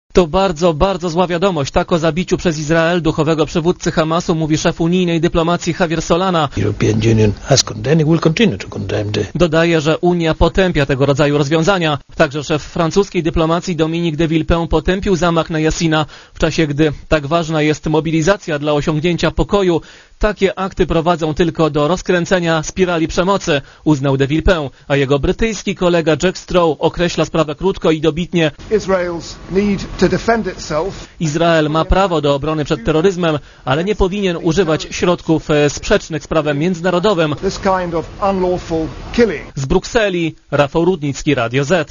Korespondencja z Brukseli Szef brytyjskiej dyplomacji Jack Straw potępił jako "nie do zaakceptowania" i "nie do usprawiedliwienia" zabicie przez armię izraelską twórcy i przywódcy duchowego Hamasu.